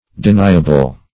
Deniable \De*ni"a*ble\, a. [See Deny.]